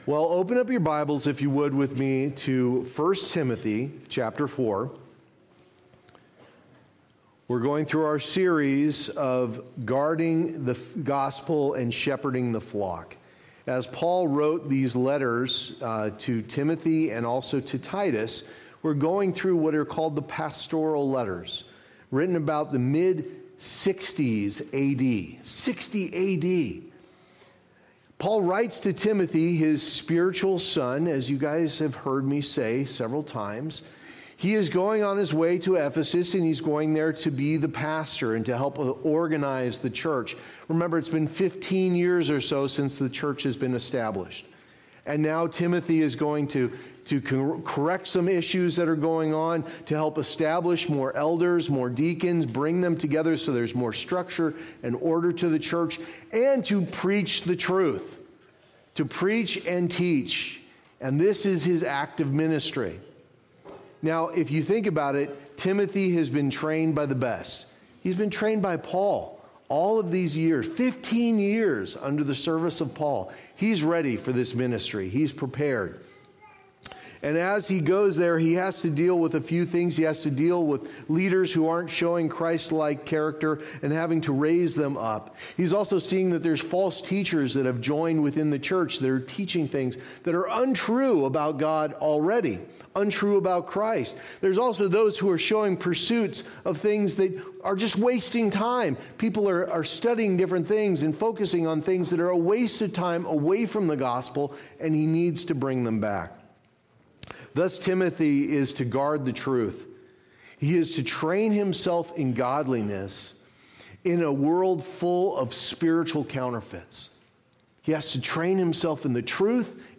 Guard the Gospel & Shepherd the Flock Passage: I Timothy 4:11-16 Services: Sunday Morning Service Download Files Notes Previous Next